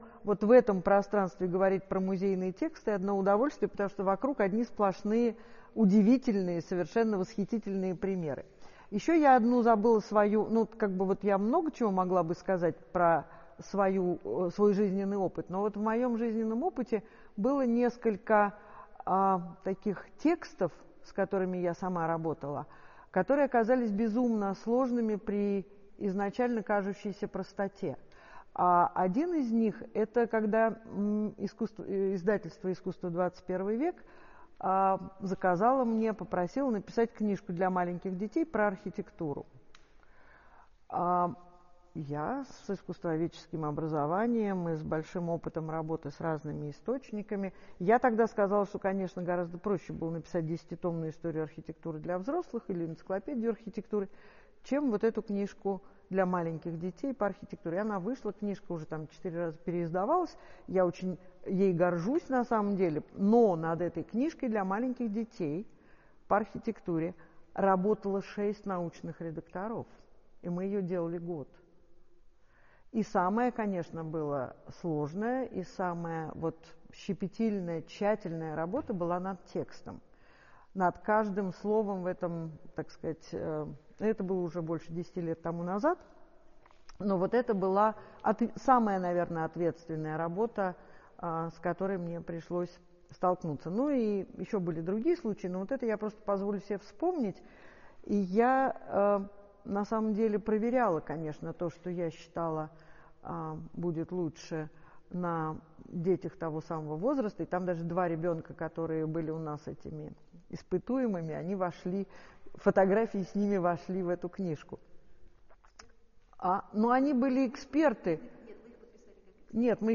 Встреча клуба «Музейный опыт»